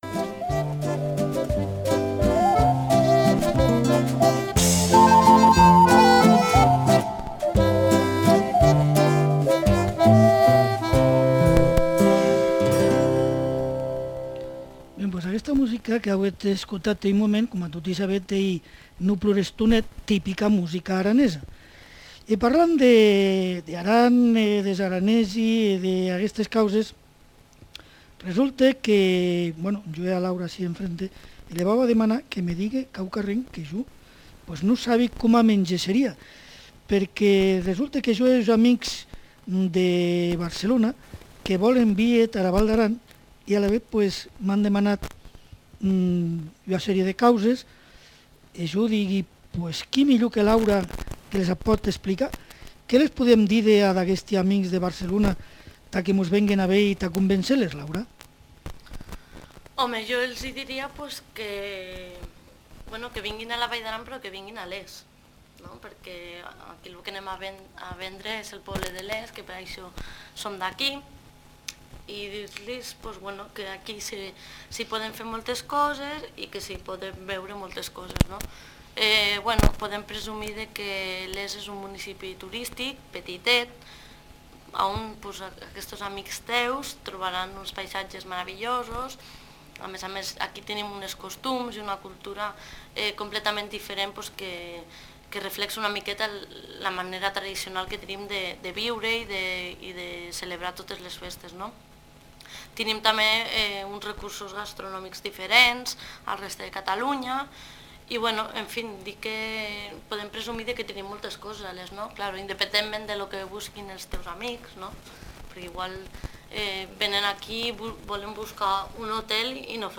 Tema musical, recomanacions turístiques de Les. Autors de l'espai i identificació de COM Ràdio
Entreteniment